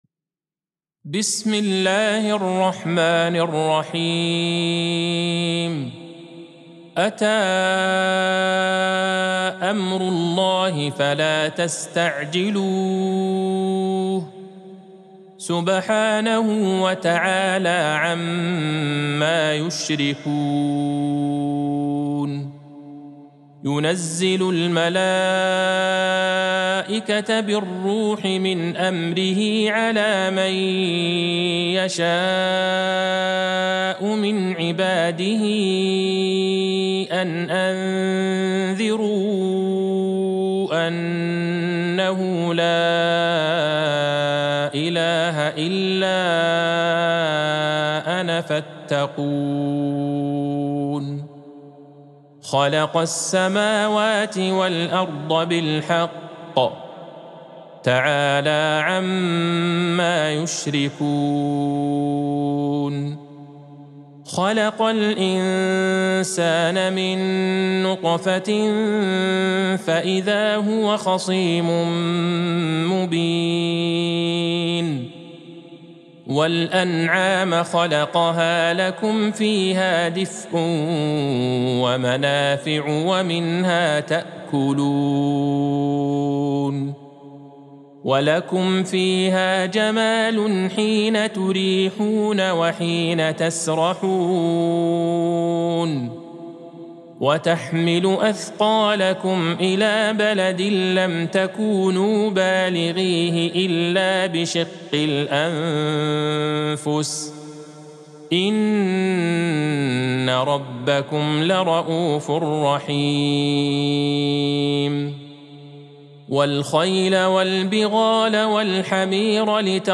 سورة النحل Surat An-Nahl | مصحف المقارئ القرآنية > الختمة المرتلة ( مصحف المقارئ القرآنية) للشيخ عبدالله البعيجان > المصحف - تلاوات الحرمين